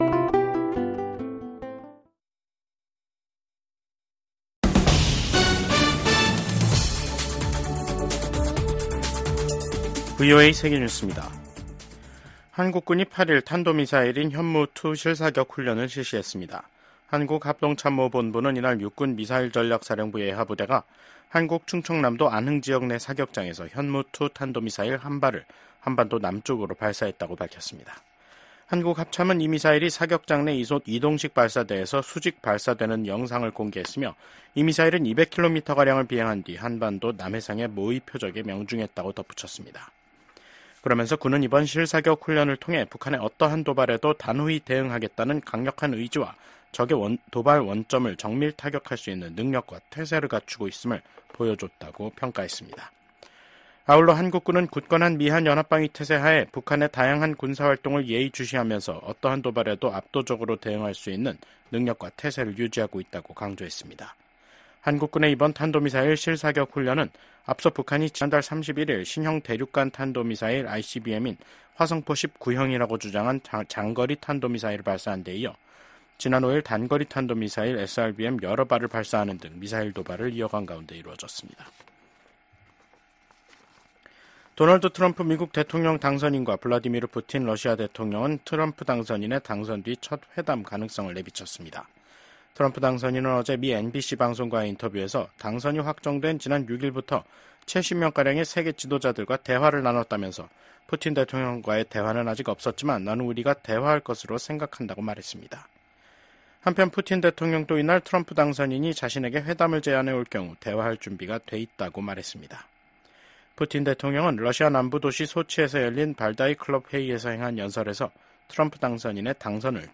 VOA 한국어 간판 뉴스 프로그램 '뉴스 투데이', 2024년 11월 8일 2부 방송입니다. 조 바이든 미국 대통령이 미국인들의 선택을 수용해야 한다며 도널드 트럼프 당선인의 대선 승리를 축하했습니다. 블라디미르 푸틴 러시아 대통령은 북한과의 합동 군사훈련이 가능하다고 밝혔습니다. 미국 국방부는 러시아에 파병된 북한군이 전장에 투입되면 합법적인 공격 대상이 된다고 경고했습니다.